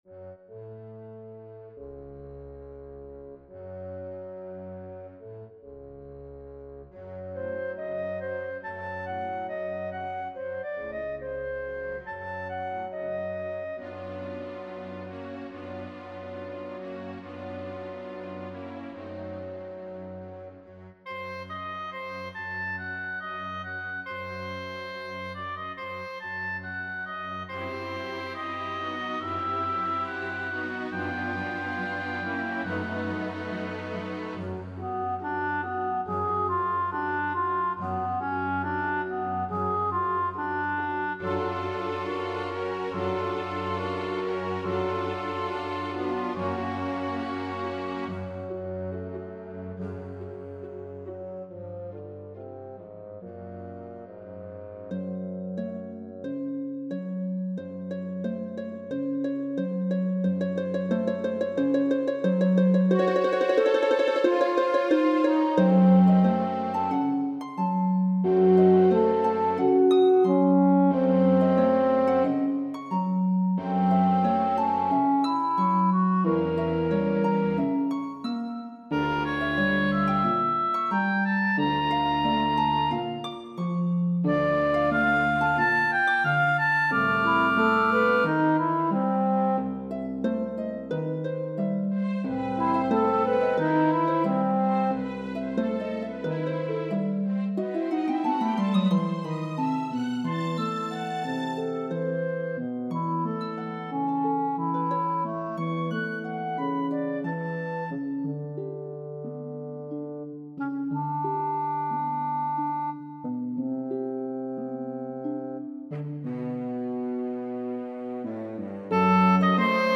harpsody for solo harp and orchestra – 12 minutes –
solo hp, 2fl, 2ob, 2bes cl, bs, 2F hn, str
harpsodie-with-orchestra.mp3